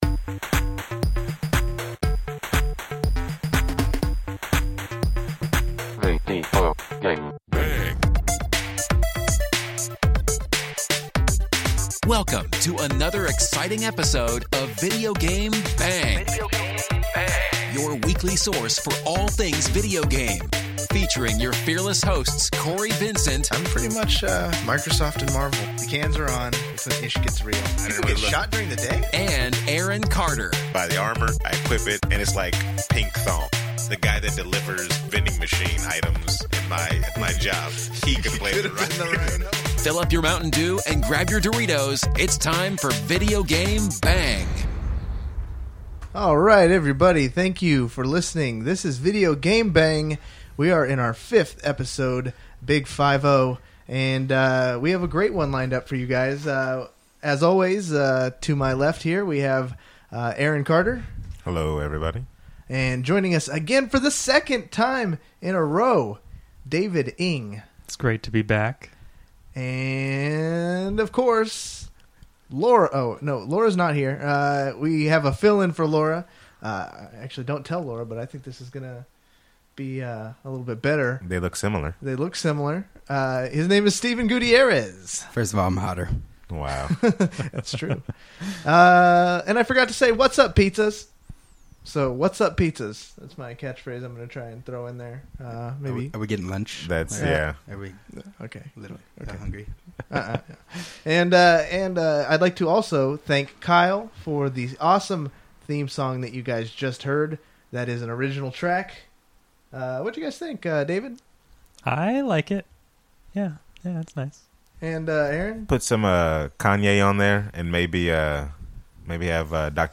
A very special episode of the podcast, we have a full studio today folks.